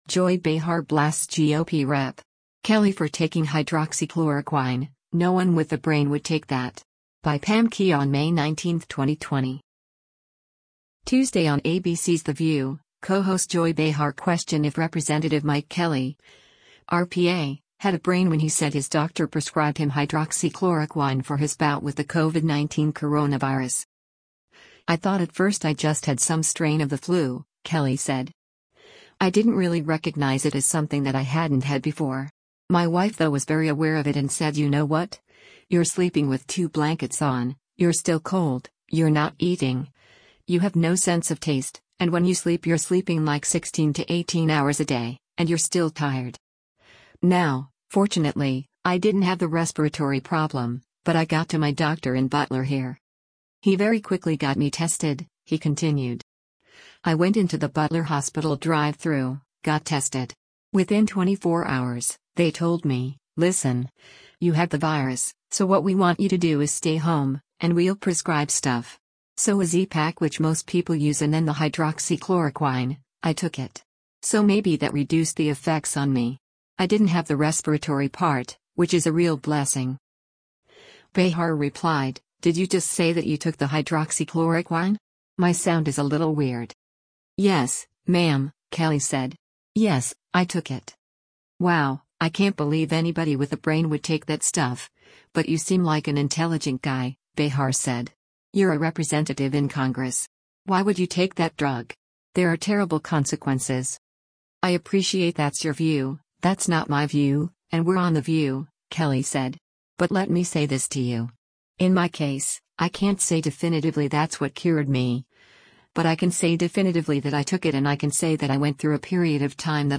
Tuesday on ABC’s “The View,” co-host Joy Behar questioned if  Rep. Mike Kelly (R-PA) had a “brain” when he said his doctor prescribed him hydroxychloroquine for his bout with the COVID-19 coronavirus.